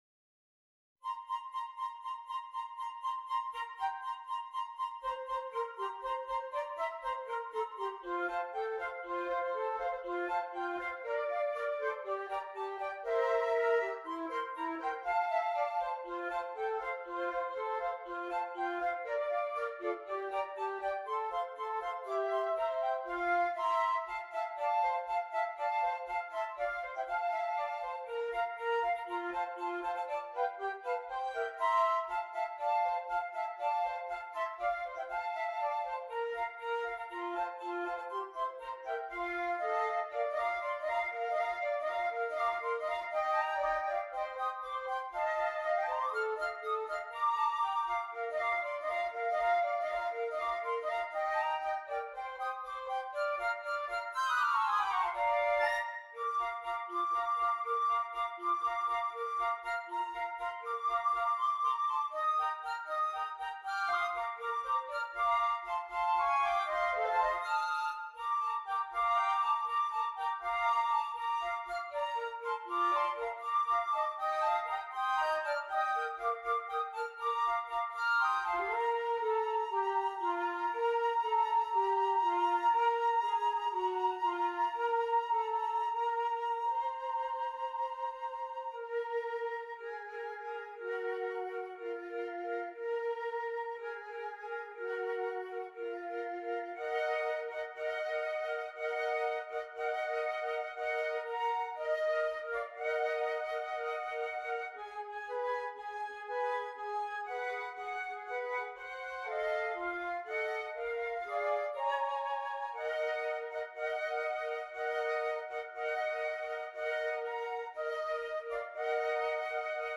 3 Flutes
Traditional